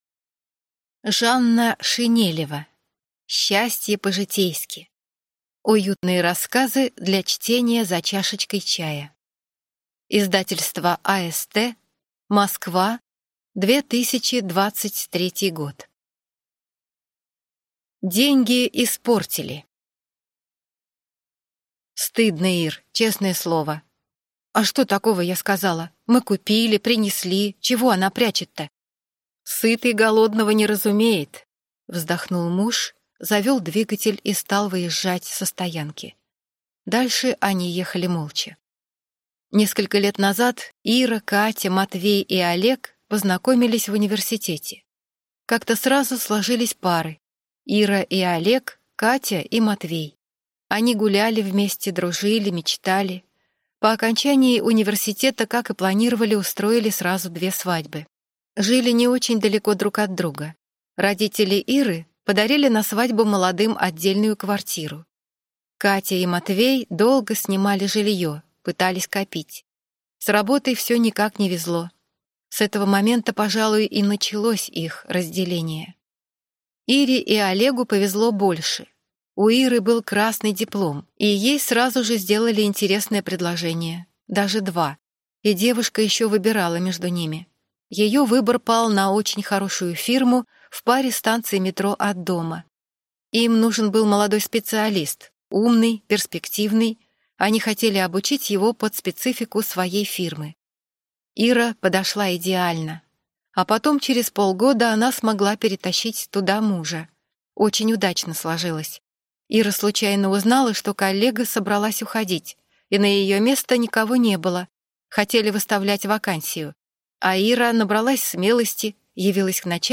Аудиокнига Счастье по-житейски. Уютные рассказы для чтения за чашечкой чая | Библиотека аудиокниг